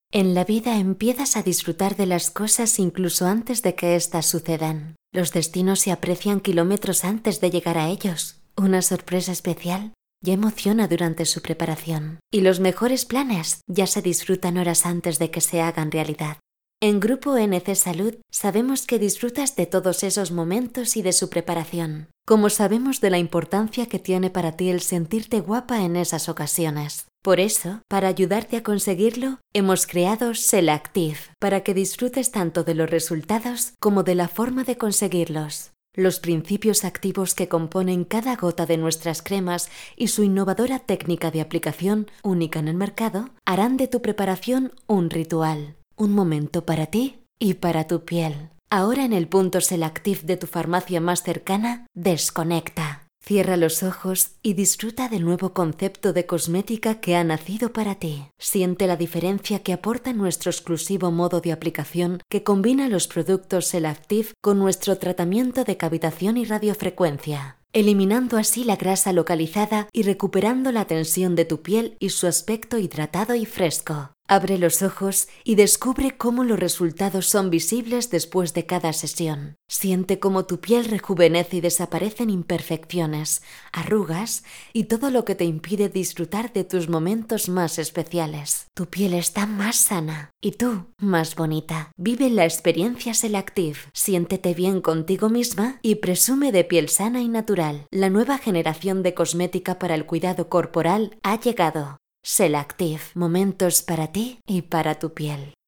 ACTRIZ DOBLAJE / LOCUTORA PUBLICITARIA / CANTANTE Voz versátil, cálida, elegante, sensual, fresca, natural, dulce, enérgica...
kastilisch
Sprechprobe: eLearning (Muttersprache):
A versatile, experienced , clear, credible and vibrant voice over artist.